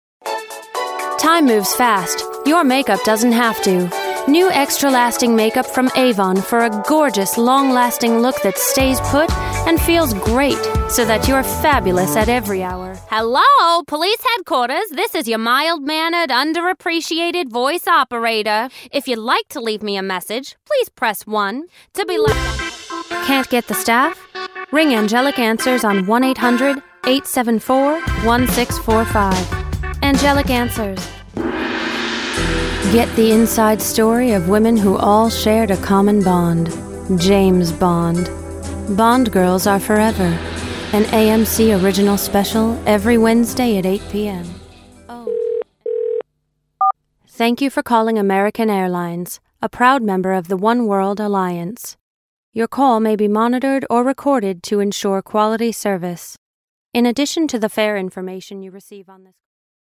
Female, American, US, 20s, 30, commercial, advert, voiceover, voice over, DGV, Damn Good Voices, damngoodvoices, Crying Out Loud, cryingoutloud,